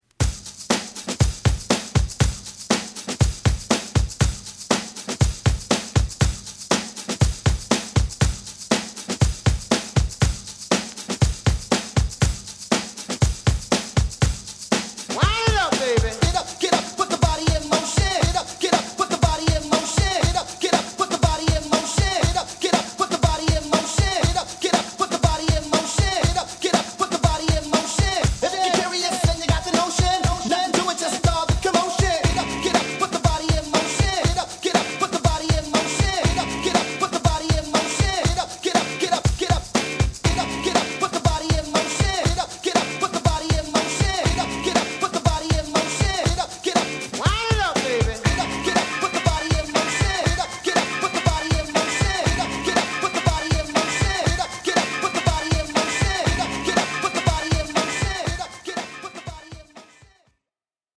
両面ともに再生は軽微なノイズを多少感じるとこはありましたが、大きな問題はなく大半は概ね良好に聞けます。